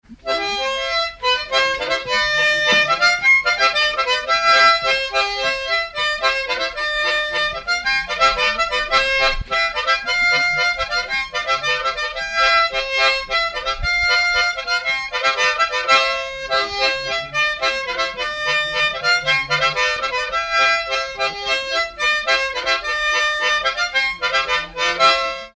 Danse
circonstance : bal, dancerie
Pièce musicale inédite